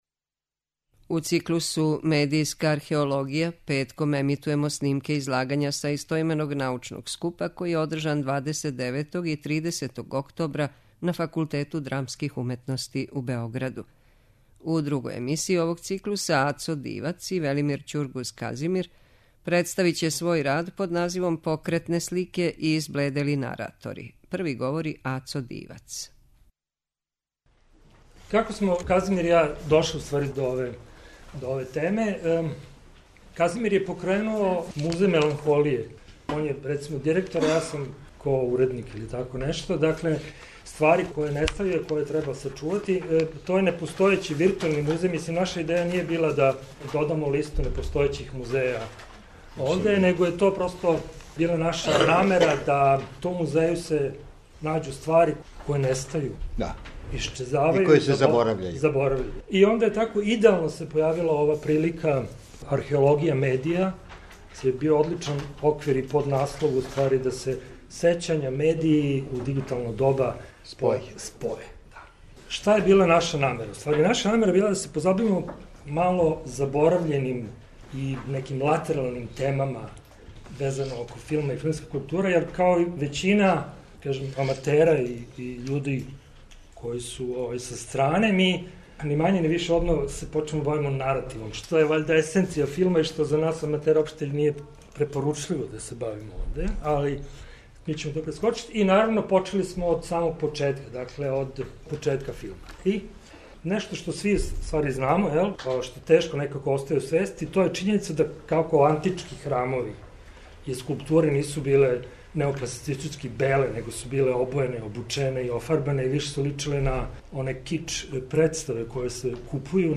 У циклусу МЕДИЈСКА АРХЕОЛОГИЈА петком ћемо емитовати снимке са истоименог научног скупа који је одржан 29. и 30. октобра на Факултету драмских уметности у Београду.
Научни скупови